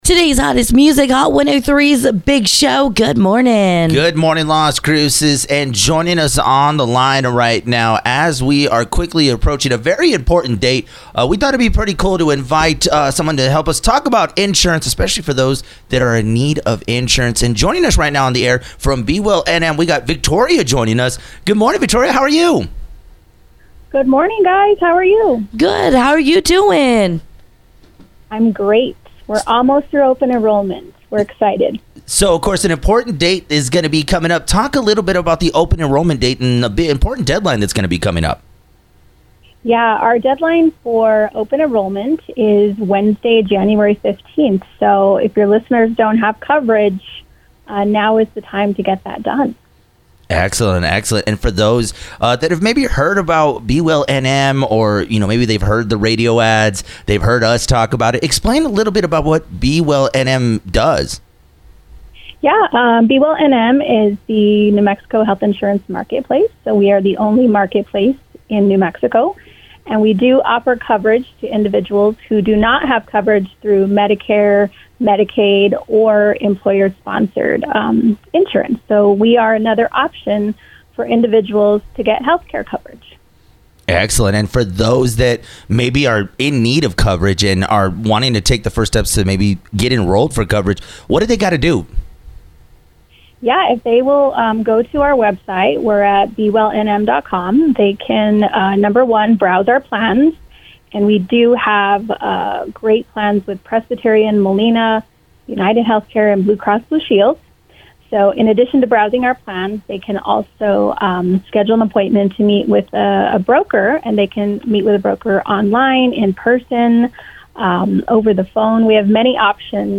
Big Show interview with Be Well NM